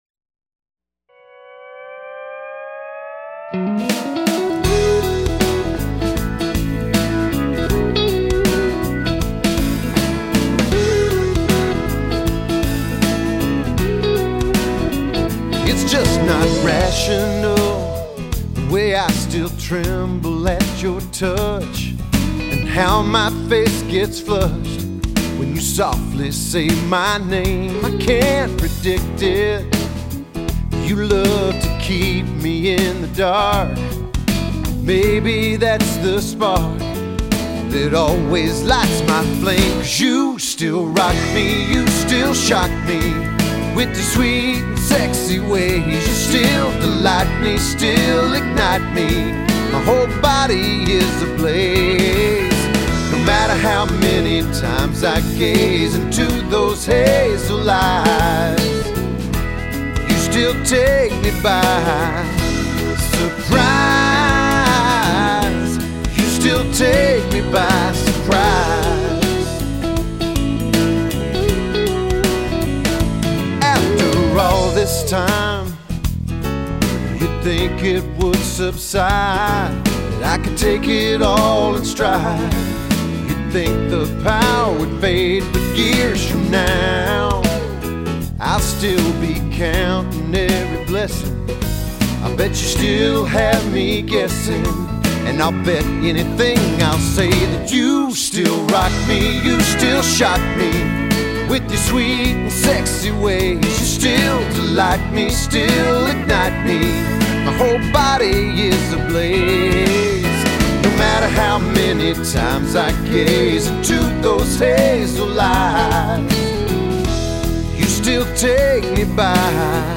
Pop/Country